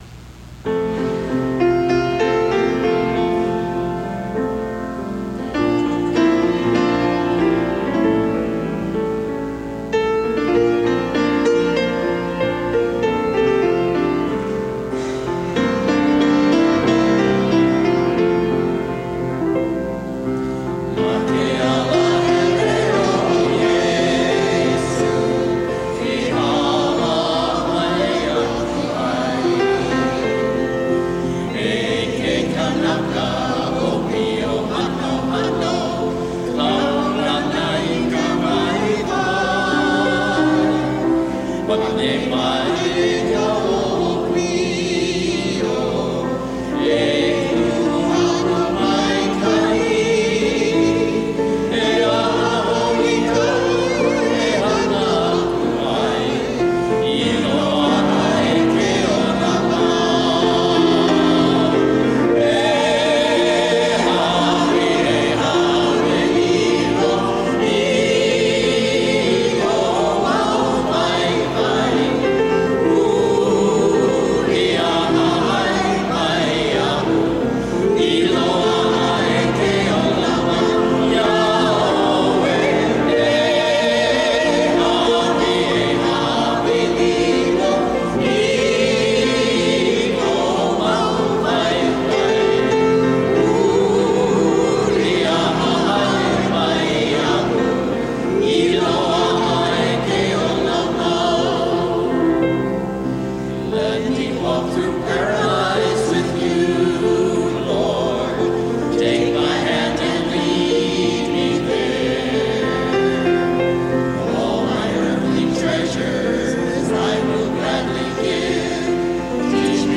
Enjoy an evening of Hawaiian-style church music by several Oahu parish choirs.
Iesü Me Ke Kanaka Waiwai  (Almeida) St. Anthony Choir
Pule I Ka Lei/Pray A Lei  (Scrbacic) Co-Cathedral Choir St. Theresa